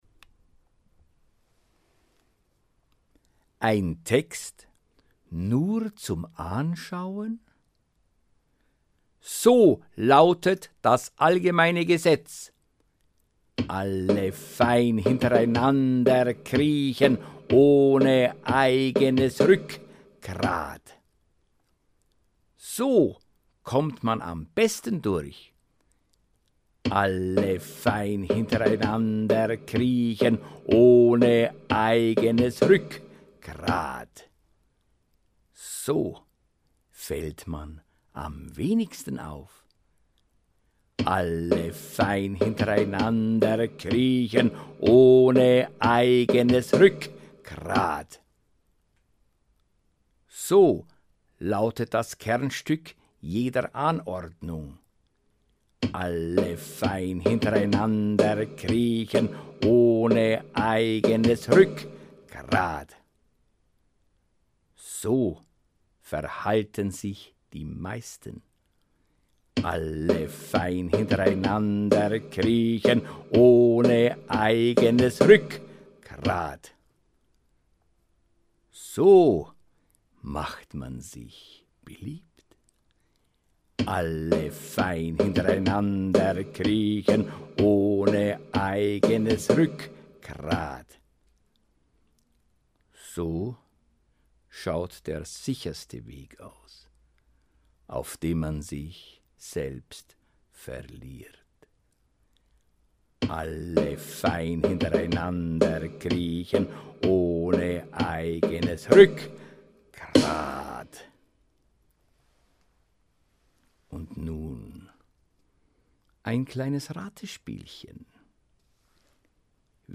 Rezitation: